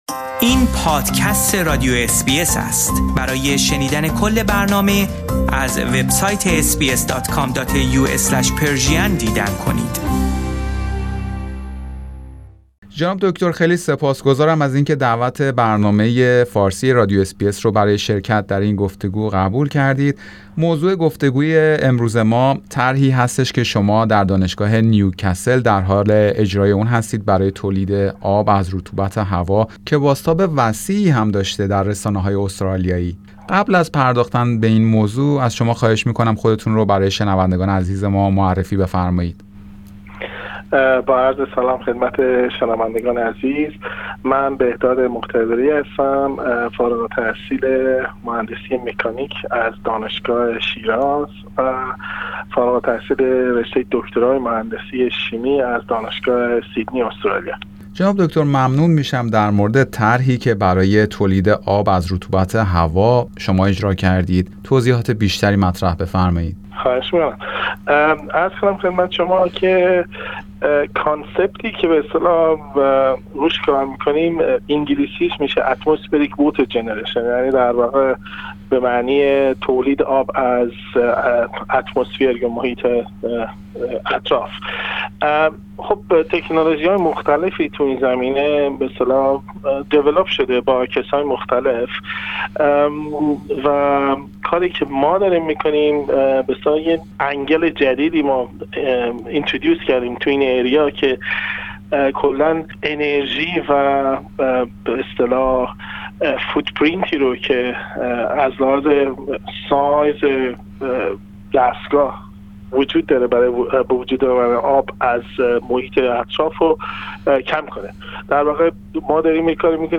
در این زمینه گفتگویی داشتیم